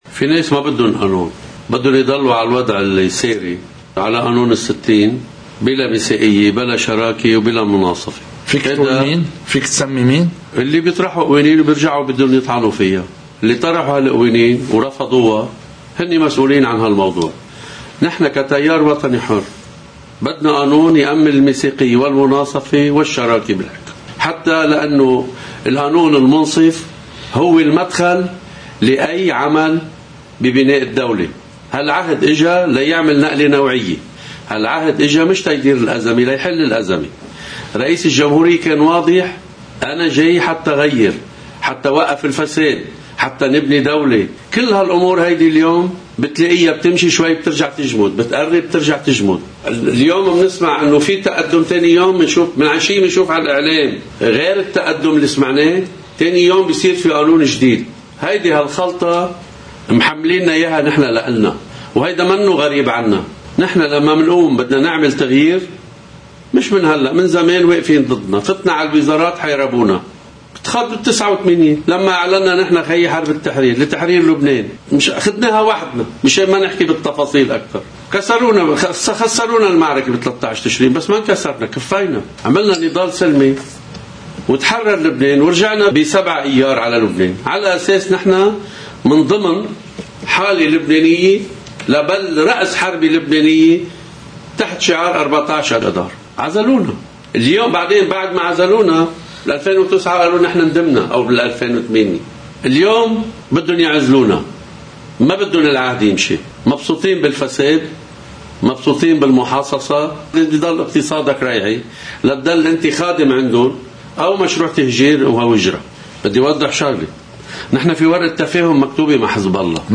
مقتطف من حديث الوزير بيار رفول لقناة الـ”OTV” ضمن برنامج “بلا حصانة”: (الجزء 1)